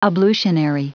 Prononciation du mot ablutionary en anglais (fichier audio)
Prononciation du mot : ablutionary